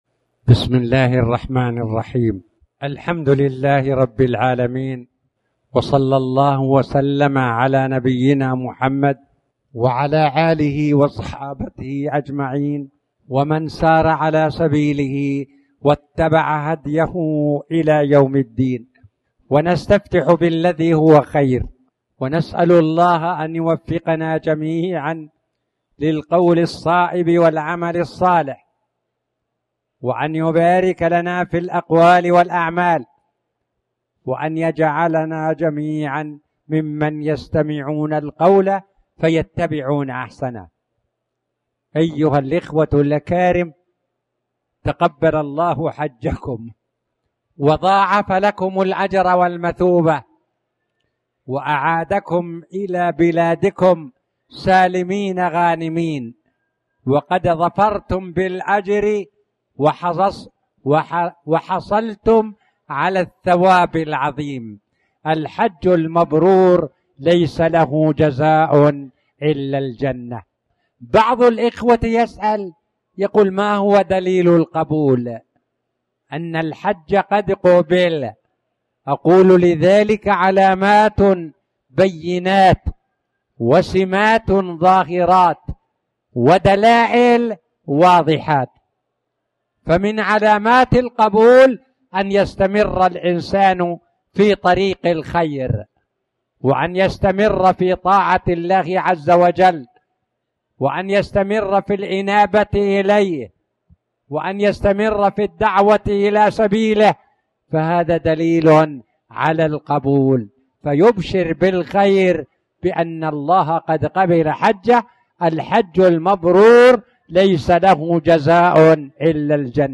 تاريخ النشر ٢٣ ذو الحجة ١٤٣٨ هـ المكان: المسجد الحرام الشيخ